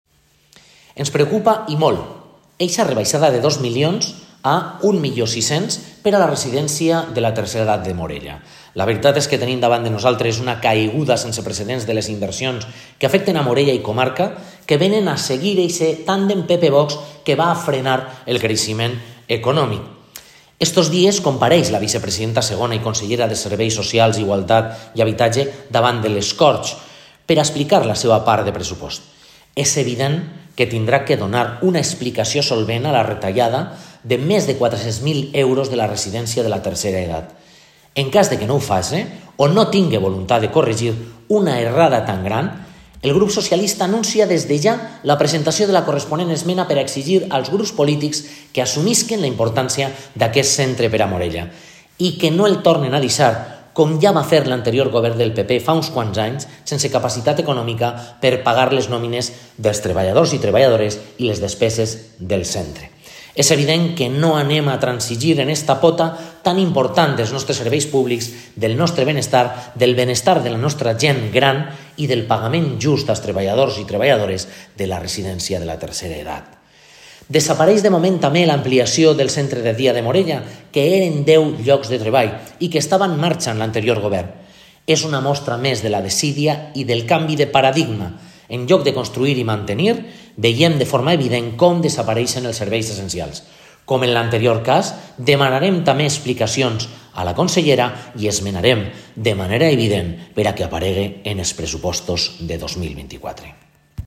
DELARACIONS-ERNEST-BLANCH-RESIDENCIA-I-CENTRE-DE-DIA-MORELLA.m4a